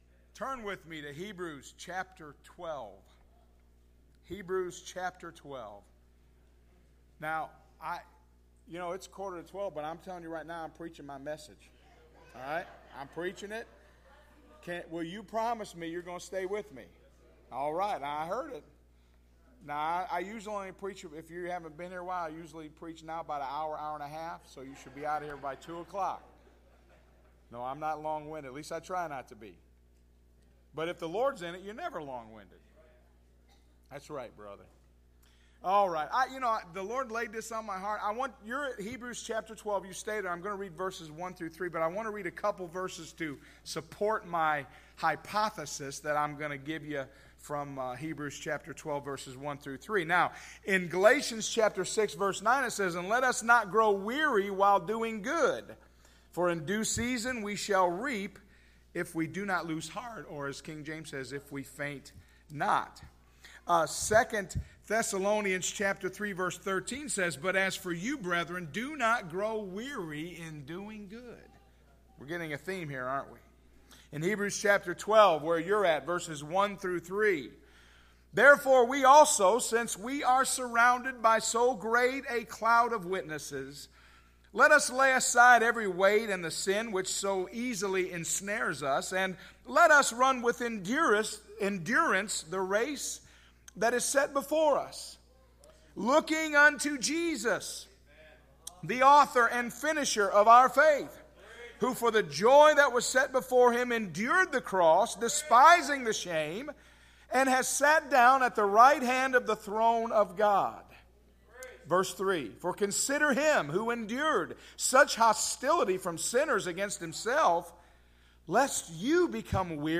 Sunday Morning - 05/28/2017 — Unity Free Will Baptist Church